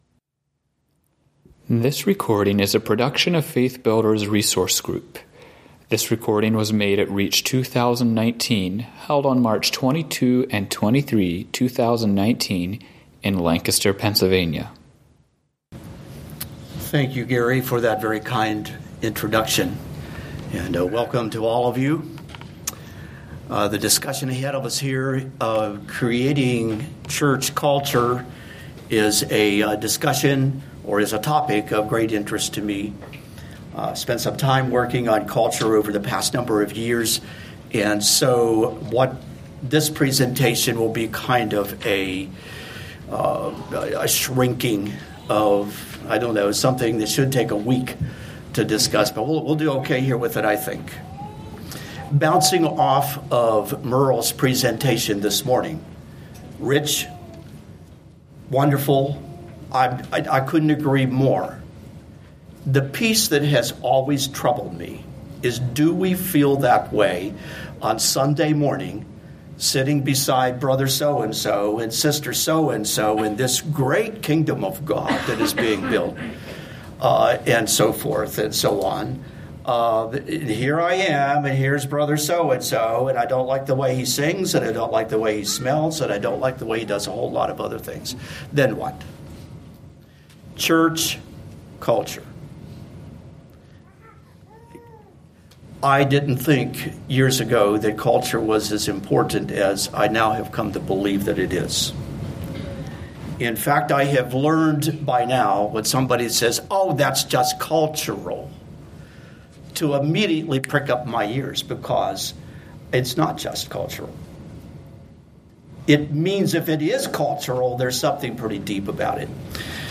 Home » Lectures » Creating Church Culture